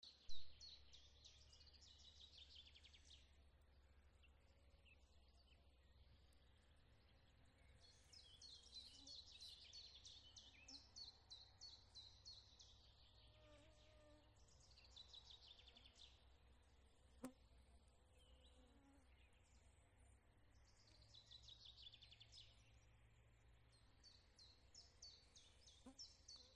Hoopoe, Upupa epops
StatusVoice, calls heard
Notes /ieraksts gan ne visai, bet dziļumā dzirdama klusa balss u-u-u, reizēm četrzilbīga, pārsvarā trīs, saucieni seko drīz viens pēc otra. punkts aptuvens, balss ap to dzirdama gan uz R gan A pusi.